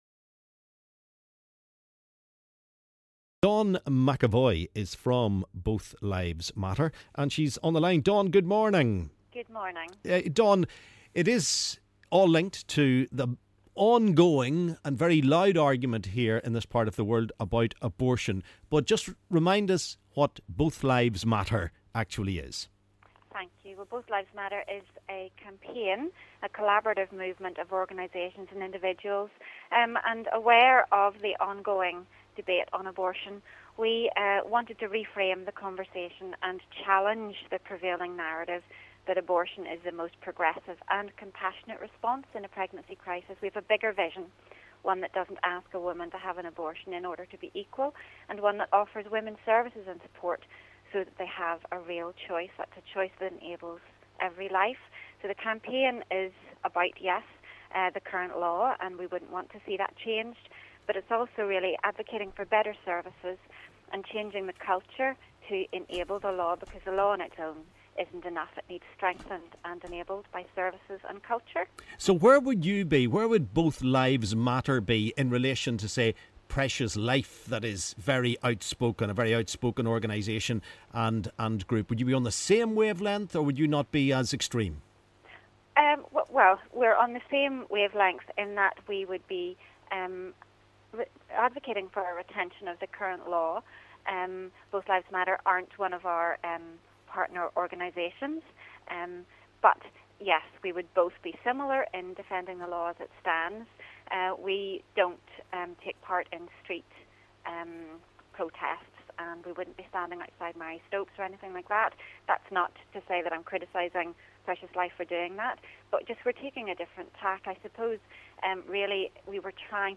LISTEN: Abortion debate as campaign group claims strict abortion laws in NI have saved 100,000 people